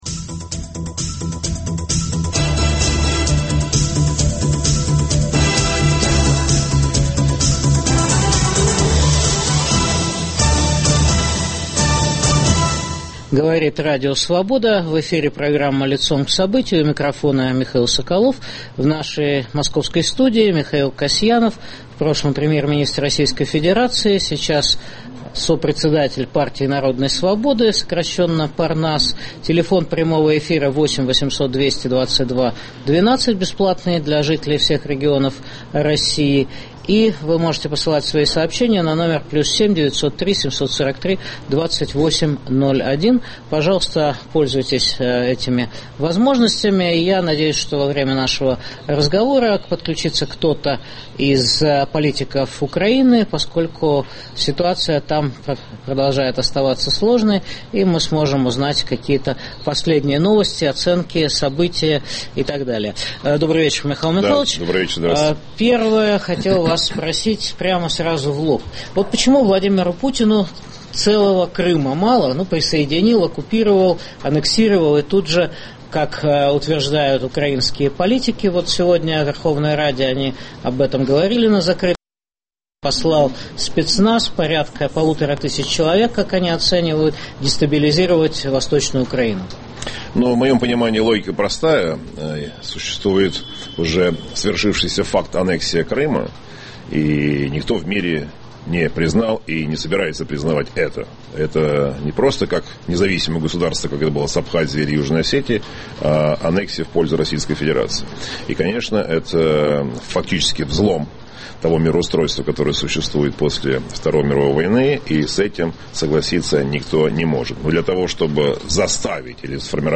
Почему Путин претендует на роль врага Запада №1? Беседуем с бывшим премьер-министром России Михаилом Касьяновым.